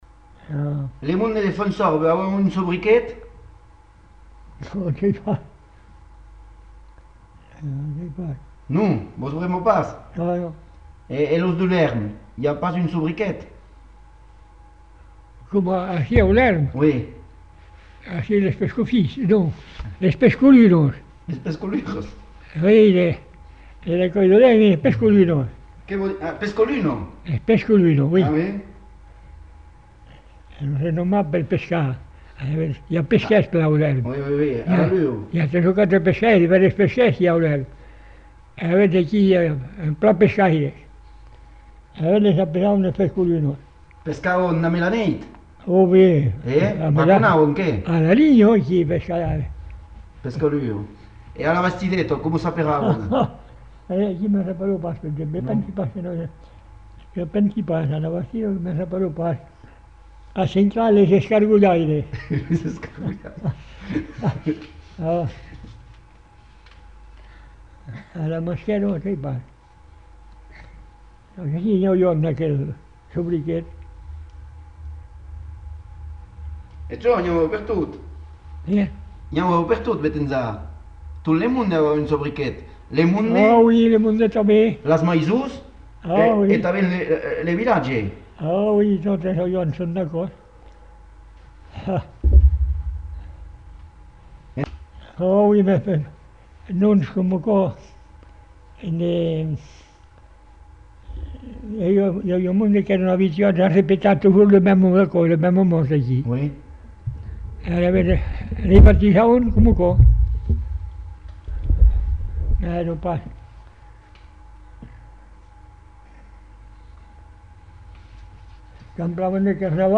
Lieu : Lherm
Genre : témoignage thématique
Langue : occitan (gascon)